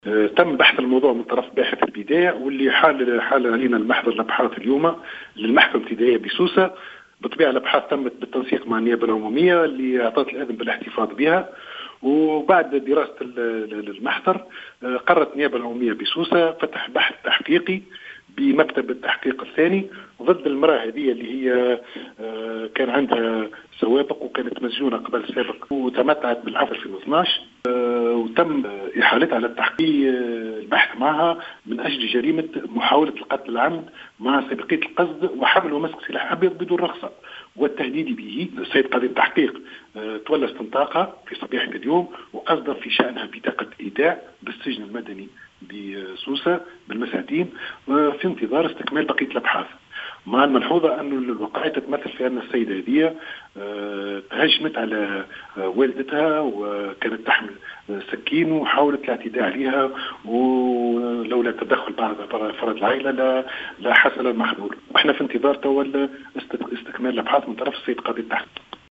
قررت النيابة العمومية بالمحكمة الابتدائية بسوسة، فتح بحث تحقيقي ضدّ امرأة حاولت قتل أمّها، و أذن قاضي التحقيق بالاحتفاظ بها، حسب ما أكدّه في تصريح للجوهرة أف أم وكيل الجمهورية بسوسة هشام بسباس.